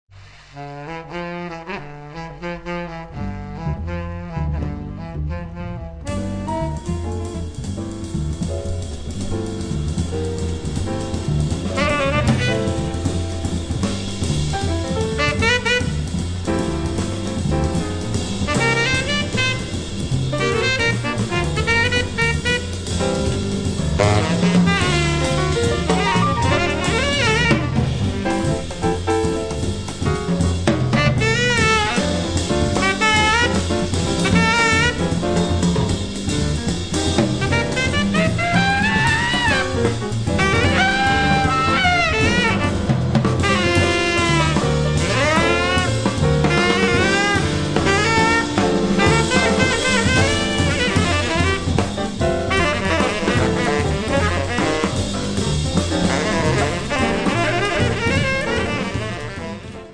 sassofoni
pianoforte
contrabbasso
batteria